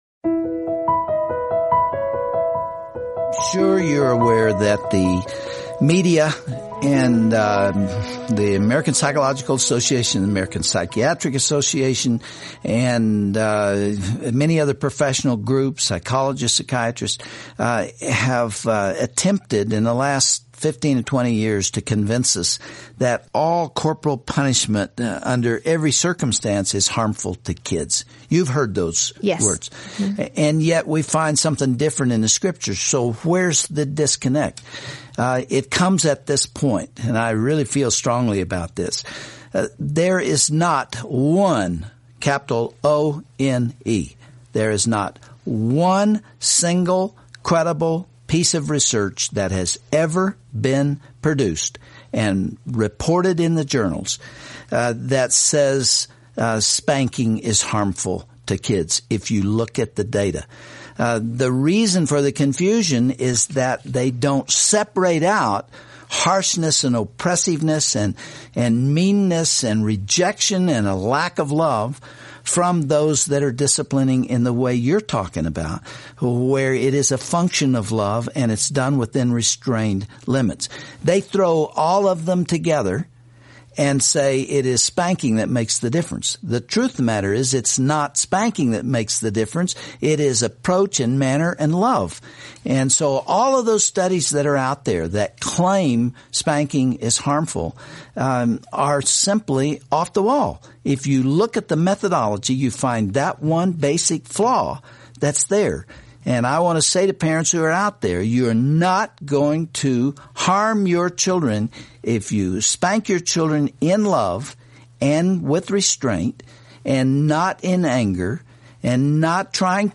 Dr. Dobson addresses this question with guest, Lisa Whelchel, on the broadcast, "Creative Ideas for Child Discipline."